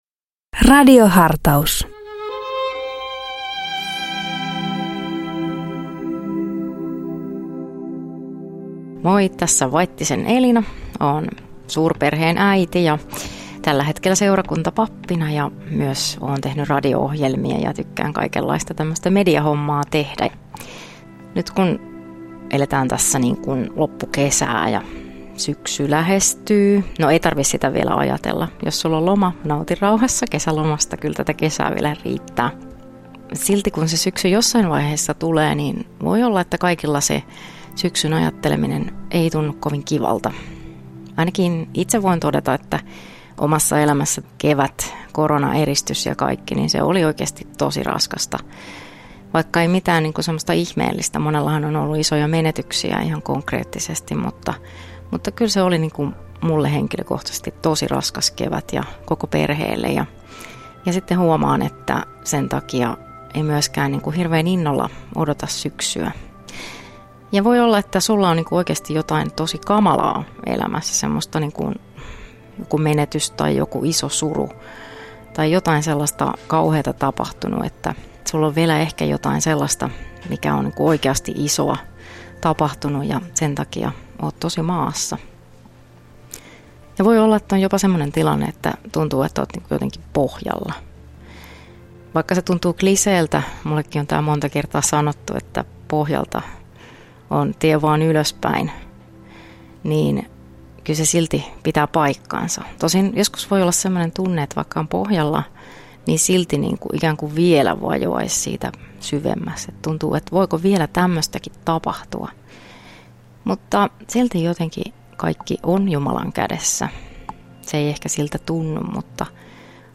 Radio Dei lähettää FM-taajuuksillaan radiohartauden joka arkiaamu kello 7.50. Radiohartaus kuullaan uusintana iltapäivällä kello 17.05.
Radio Dein radiohartauksien pitäjinä kuullaan laajaa kirjoa kirkon työntekijöitä sekä maallikoita, jotka tuntevat radioilmaisun omakseen.